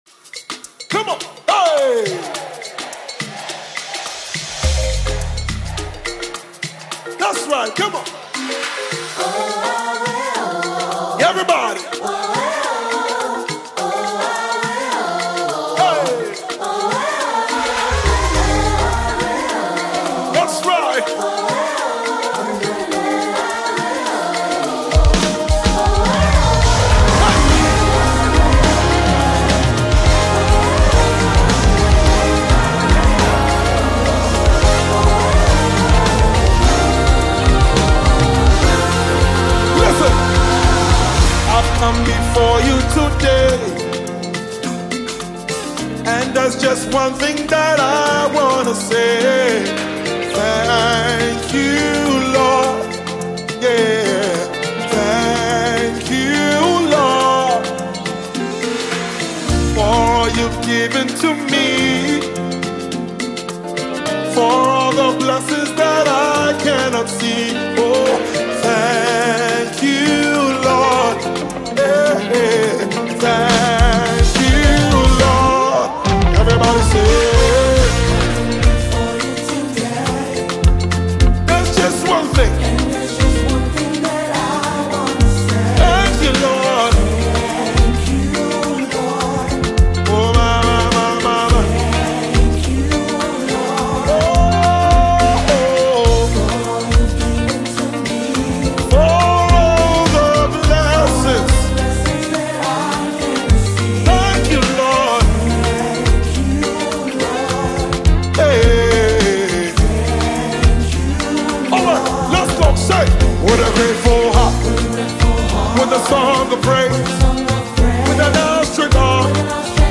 January 17, 2025 Publisher 01 Gospel 0